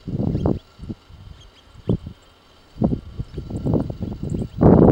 Jilguero de Monte (Sicalis mendozae)
Nombre en inglés: Monte Yellow Finch
Localidad o área protegida: Tafí del Valle
Condición: Silvestre
Certeza: Vocalización Grabada
jilguero-del-monte.mp3